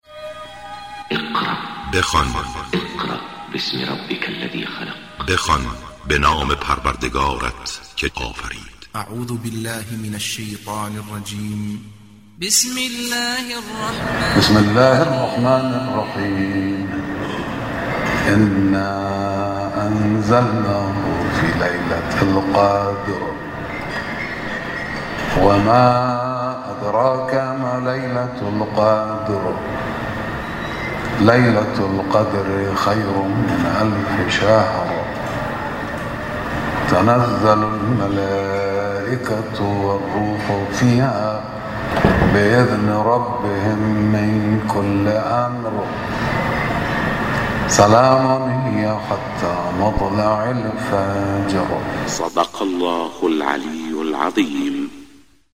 تلاوت زیبای سوره مبارکه قدر با صدای دلنشین حضرت آیت الله امام خامنه ای
در این بخش از ضیاءالصالحین، تلاوت زیبای سوره قدر را با صدای دلنشین حضرت آیت الله امام خامنه ای به مدت 1 دقیقه با علاقه مندان به اشتراک می گذاریم.